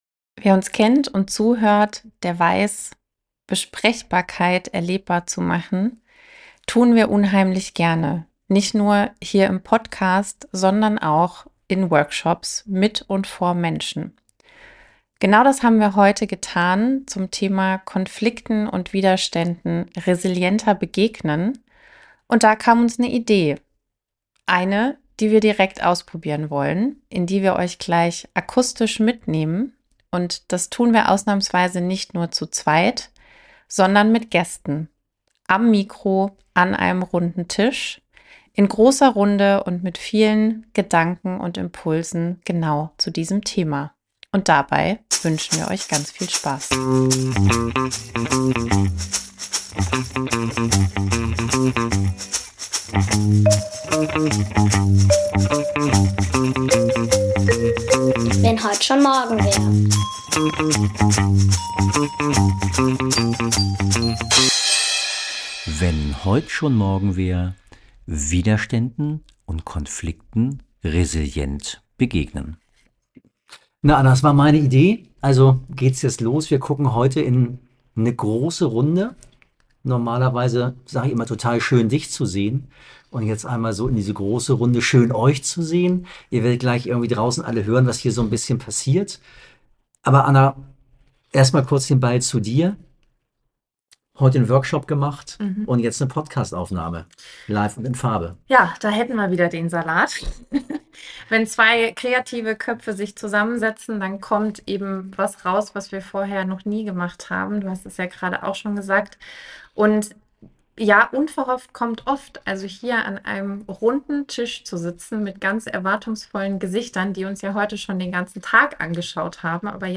Live aus unserem Workshop